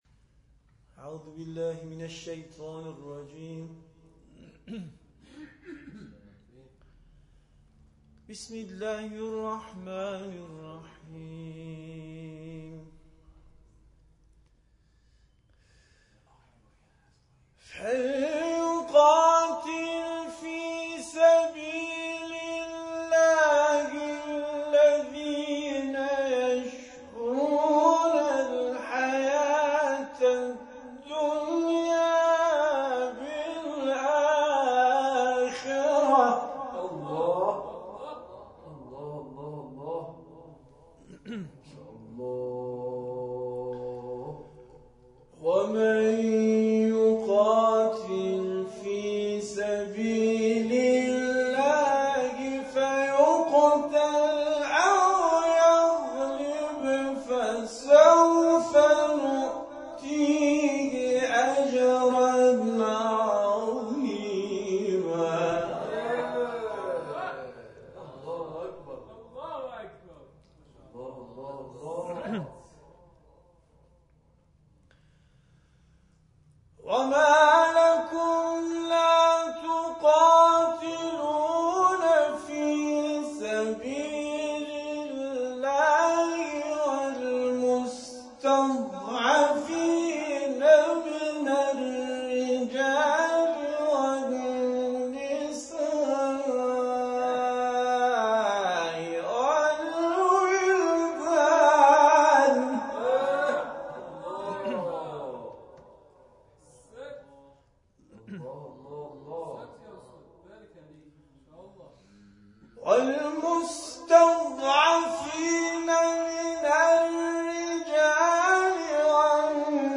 جلسه قرآن
به تلاوت آیاتی از ادامه درس جلسه پرداختند و مورد تشویق حاضران قرار گرفتند